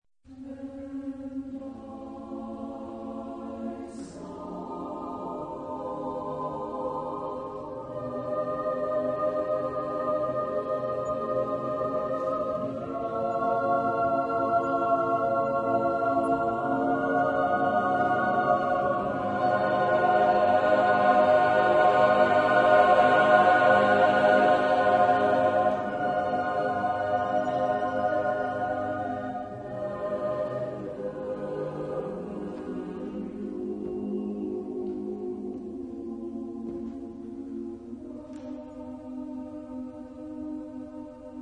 Epoque: 20th century
Type of Choir: SSATBB  (6 voices )